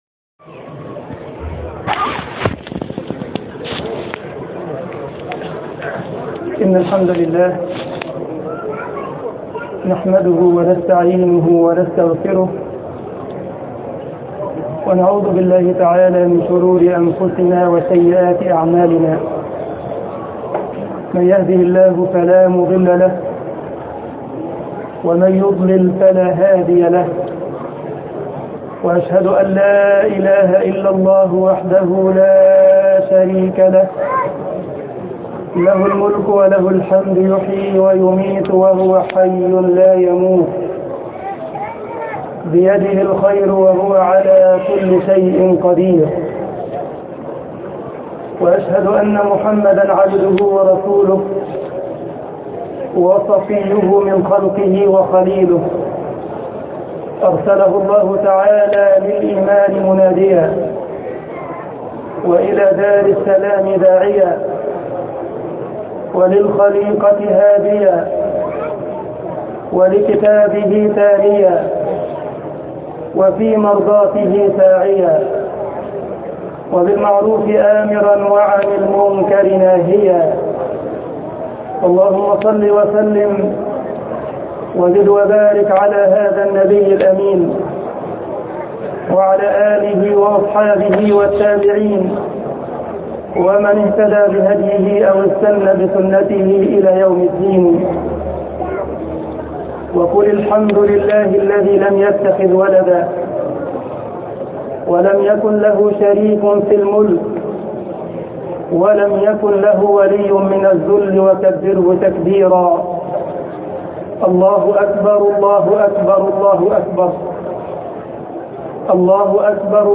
خطبة العيد
جمعية الشباب المسلمين بالسارلند - ألمانيا